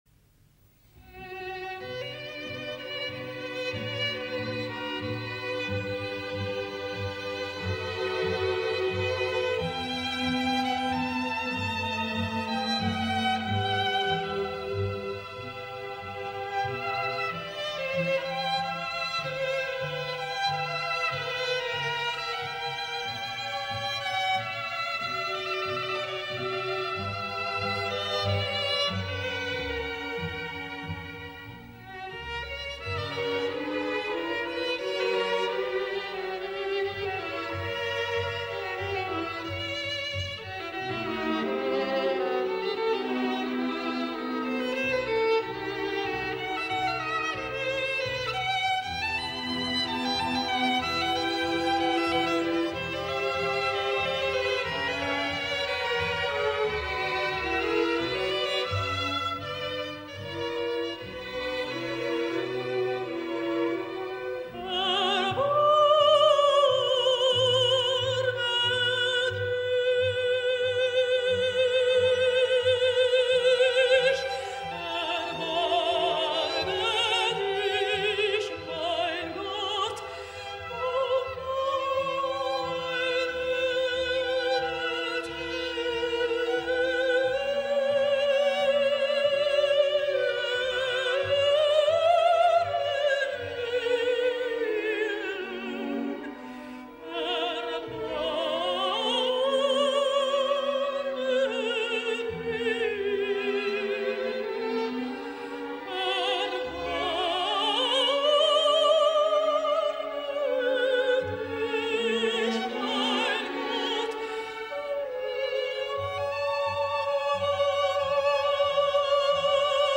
Julia Hamari